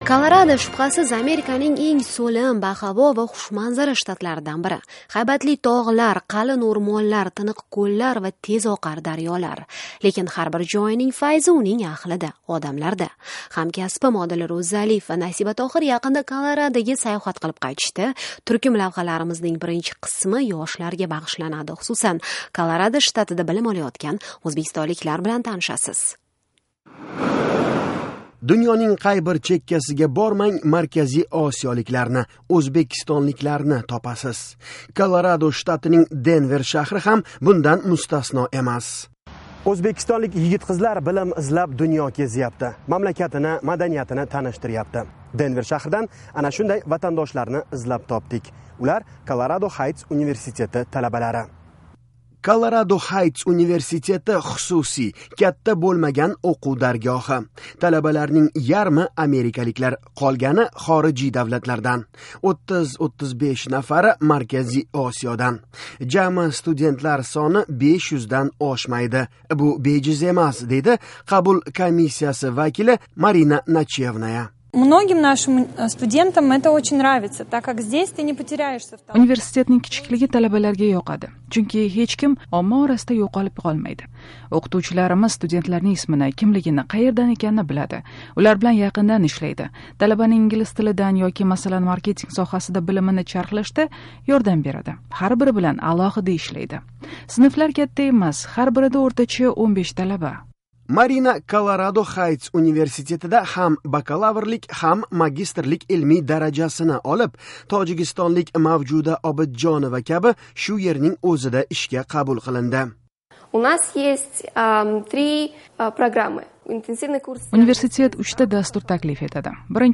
Markaziy osiyoliklar Koloradoda. Videoreportaj. 1-qism.